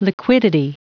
Prononciation du mot liquidity en anglais (fichier audio)